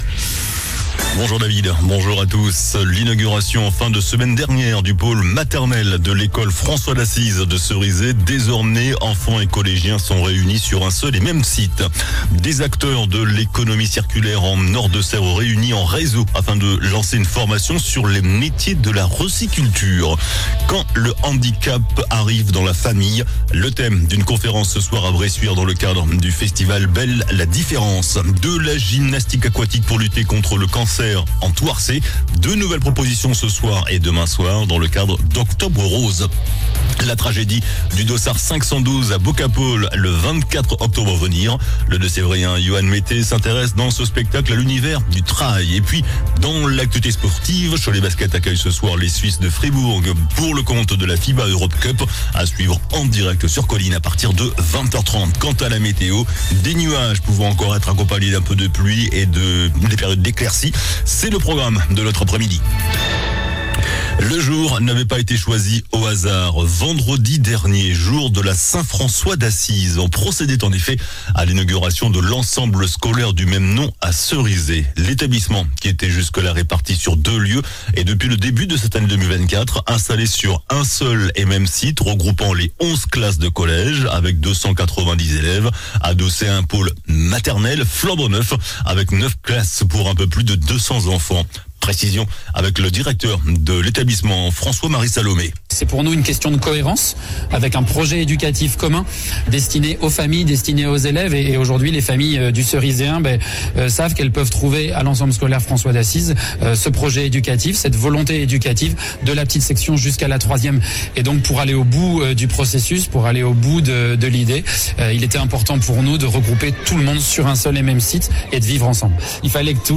JOURNAL DU MARDI 08 OCTOBRE ( MIDI )